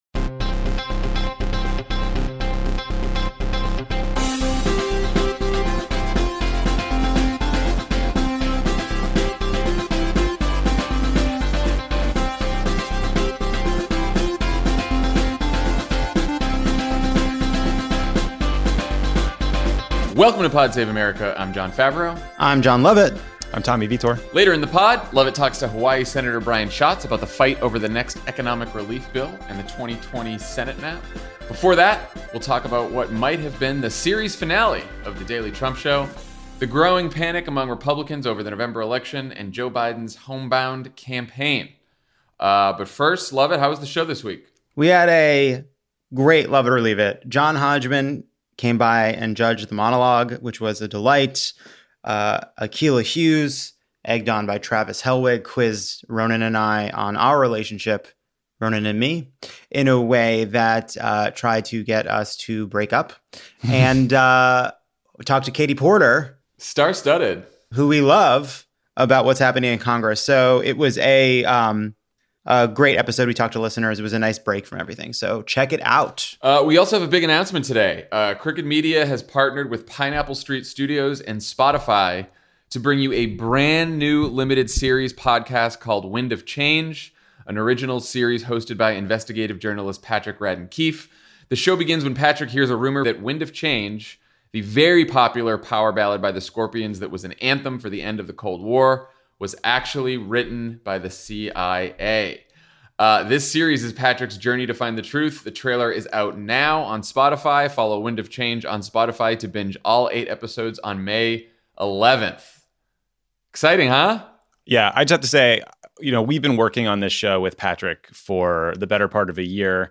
The White House weighs cutting back on Trump’s daily briefings, Republicans start to worry about November, and Joe Biden’s campaign adjusts to life in the midst of a pandemic. Then Senator Brian Schatz talks to Jon L. about the next economic relief bill and the Senate map in 2020.